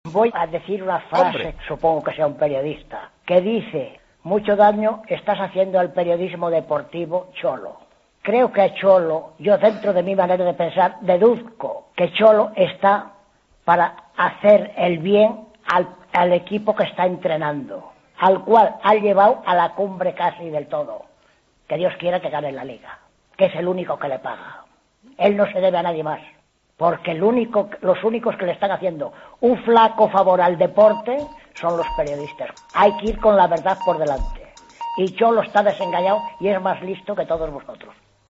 Oyente enfurecido: "El 'Cholo' es más listo que todos vosotros"